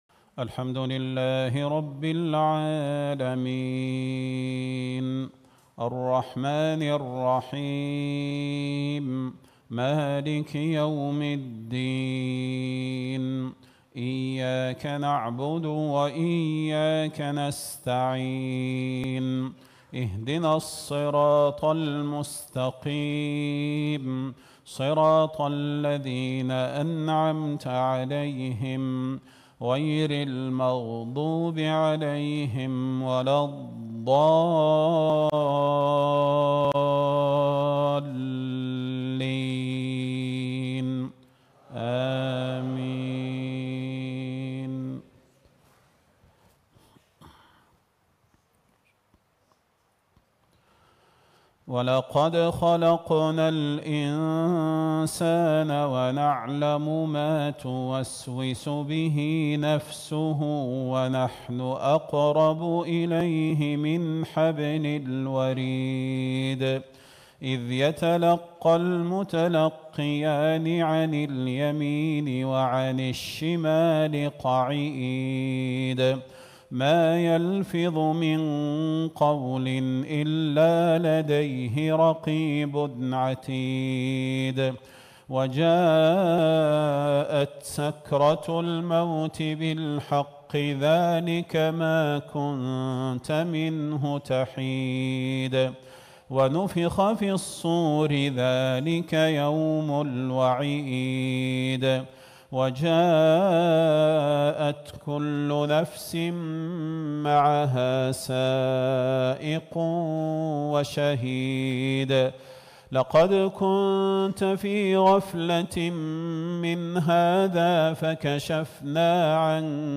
صلاة العشاء ٢٥ شوال ١٤٤٦ في جامع الملك سلمان -حفظه الله- في جزر المالديف.